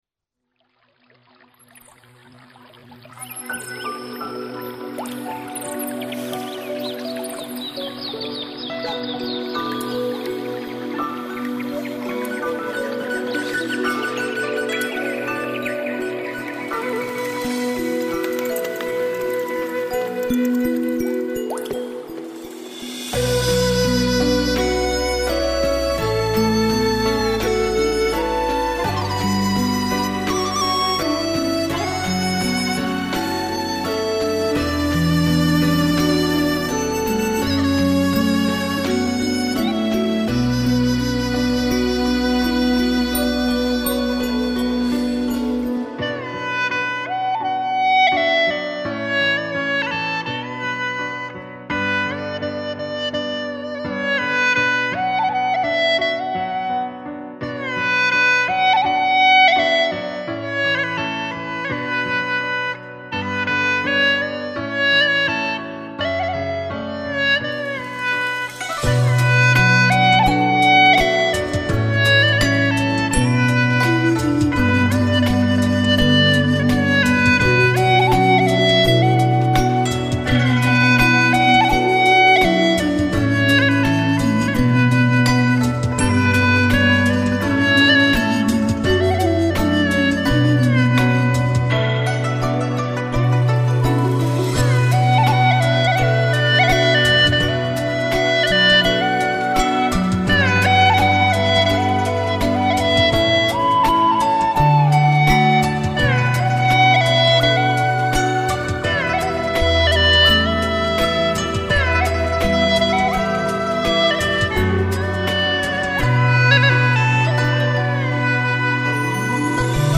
调式 : D 曲类 : 独奏